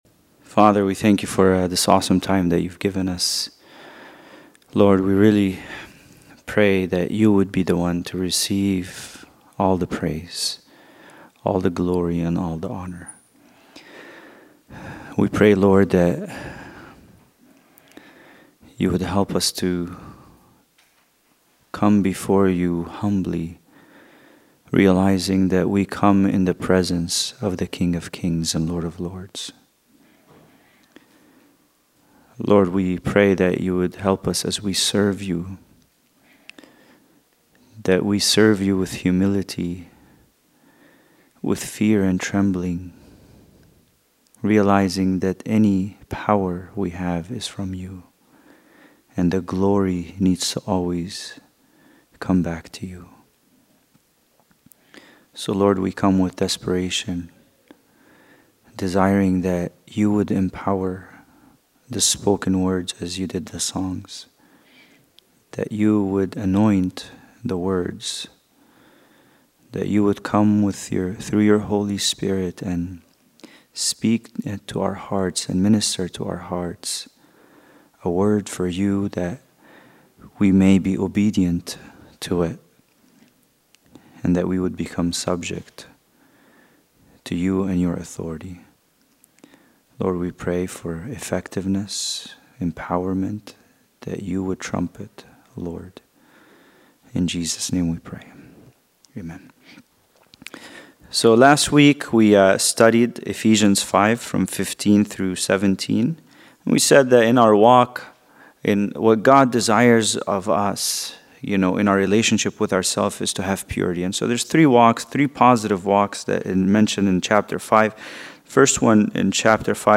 Bible Study: Ephesians 5:18